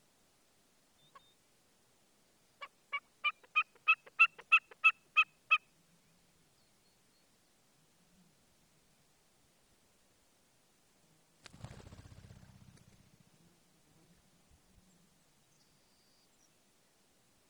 A Wilson's Snipe produces it's characteristic 'jerky' vocal calls, then takes off from the shoreline of Contact Lake, Katmai National Park.
Alaskan Natural Sound Showcase